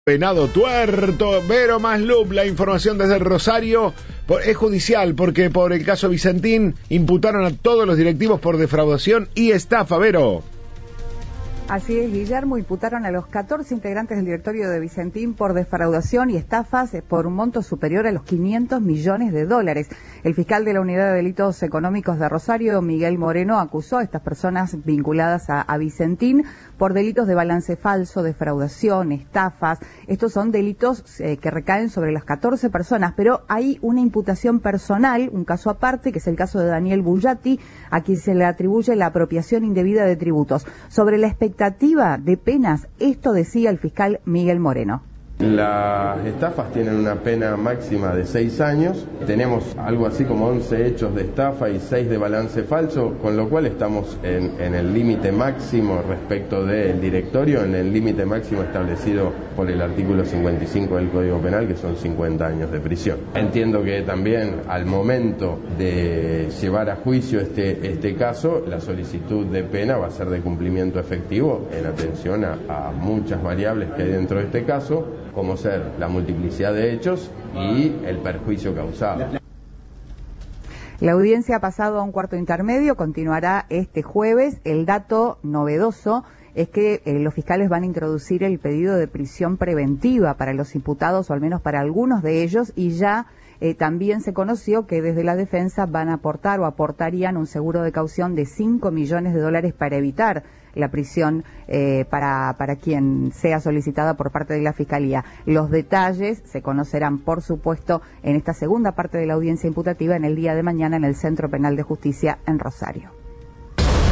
La imputación incluye a los 14 miembros y la pena podría alcanzar hasta 50 años, según explicó a Cadena 3 el fiscal de Rosario, Miguel Moreno.